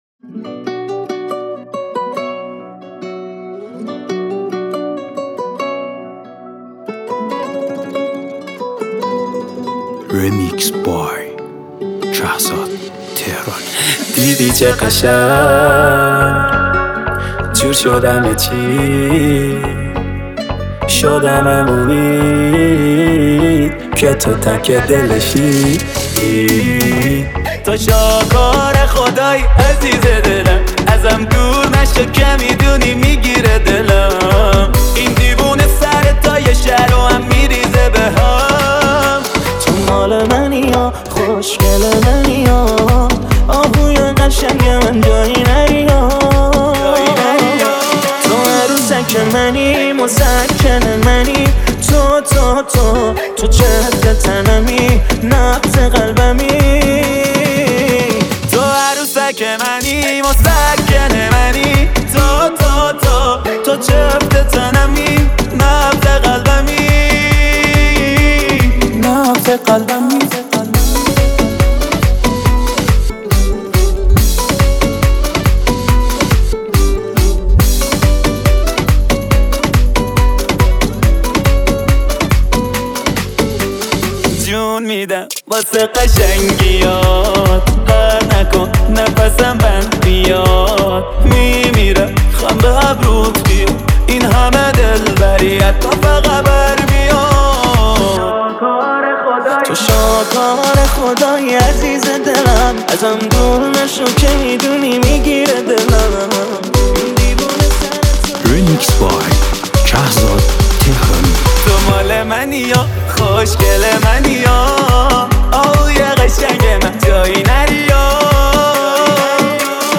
Download New Remix